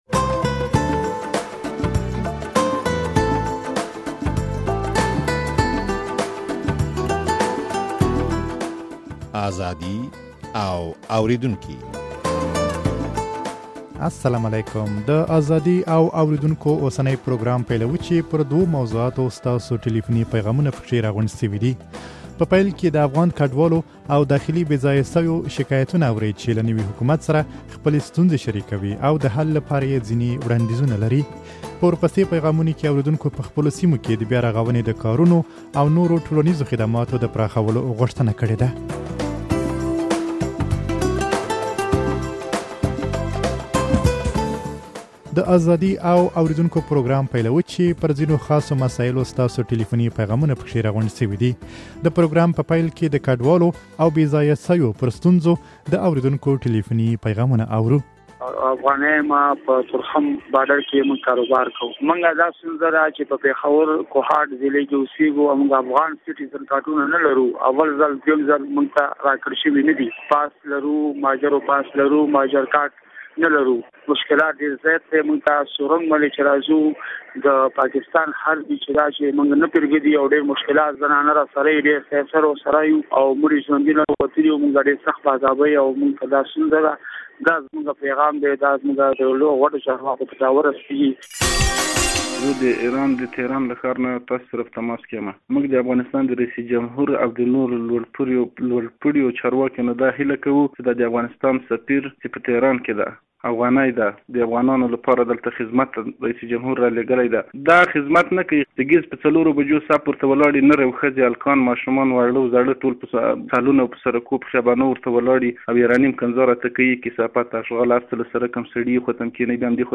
د ازادي او اورېدونکو اوسنى پروګرام پيلوو، چې پر دوو موضوعاتو ستاسو ټليفوني پيغامونه په کې راغونډ شوي دي.